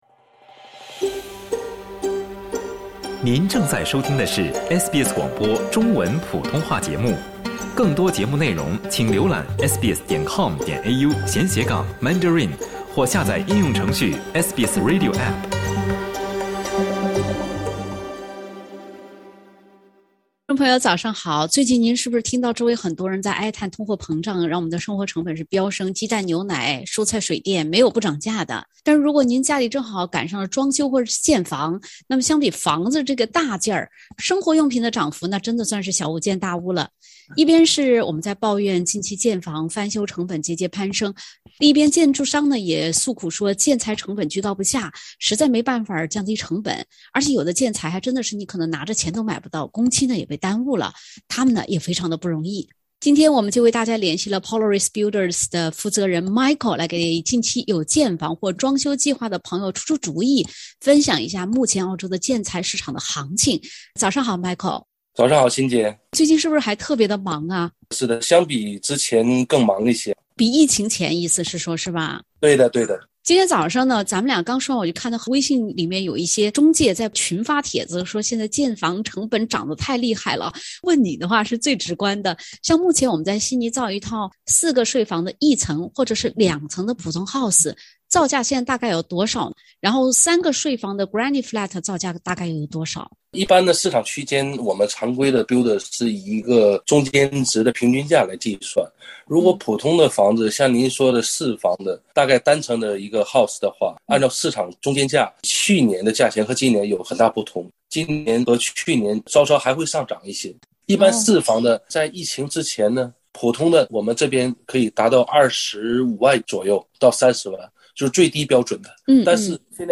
（點擊封面圖片，收聽完整寀訪） 各位朋友，最近您是不是聽到週圍很多人哀歎通貨膨脹導致生活成本飙升。